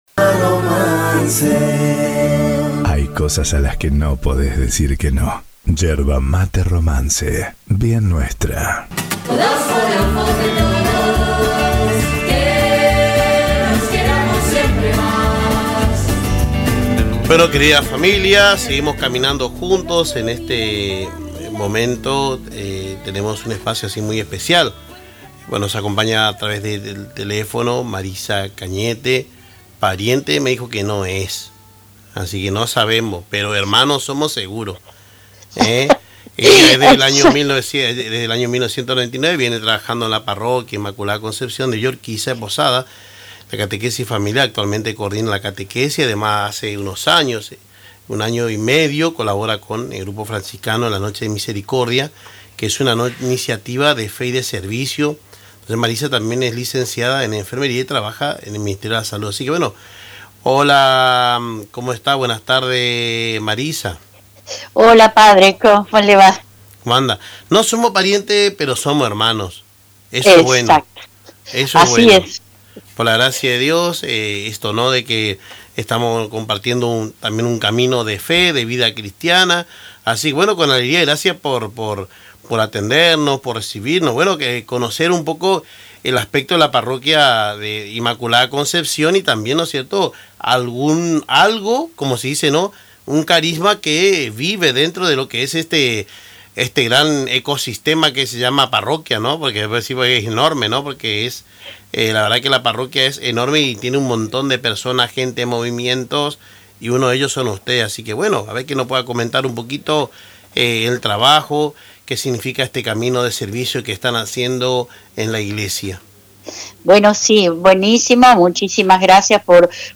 En diálogo telefónico con el programa Caminando Juntos por Radio Tupambaé